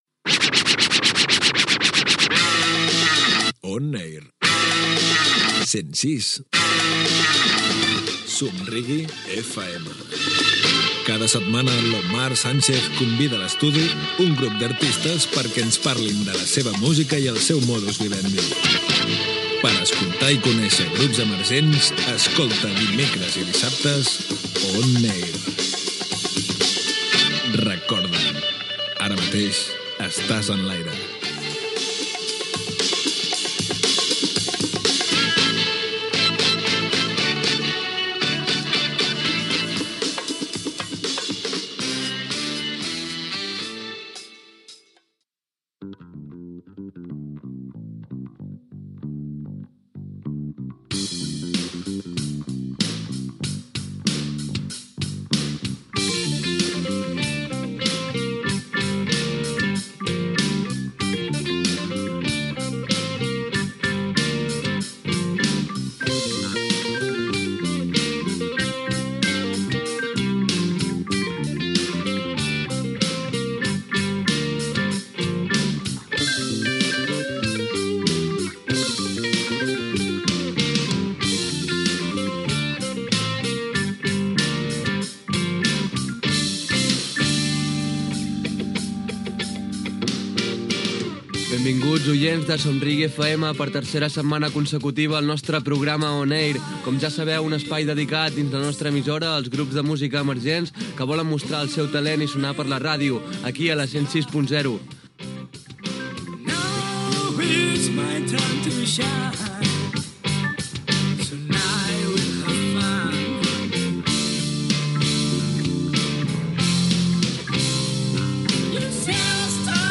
Careta del programa, presentació de l'entrevista als integrants del grup musical Happy Collyflowers amb identificació de l'emissora, indicatiu del programa
Musical